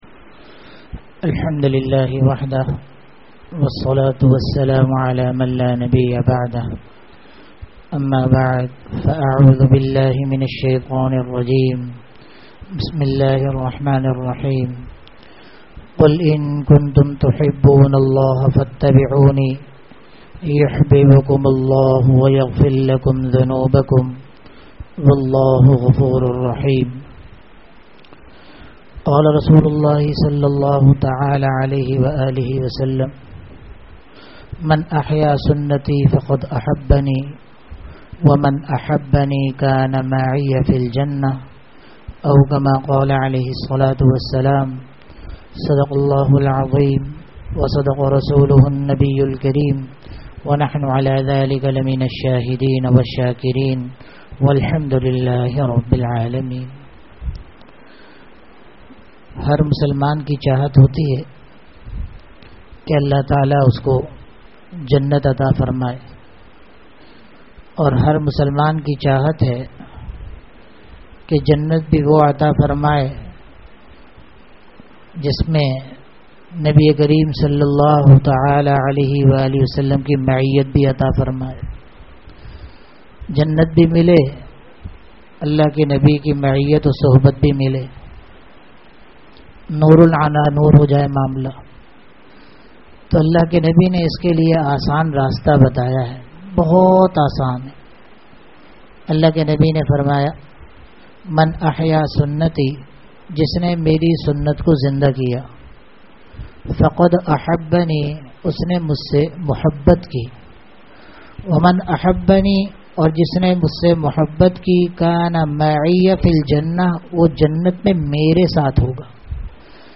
Bayanat
Bayanat Bayanat (Jumma Aur Itwar) Dunya or aakhrat ki kamyabi Houzoor s a w w ki sunnaton me hi he (jummah byan) 20th March 2026 Category Bayanat Sub-Category Bayanat (Jumma Aur Itwar) Date 20th March 2026 Size 7.29 MB Tags: Download Source 1 Download Source 2 Share on WhatsApp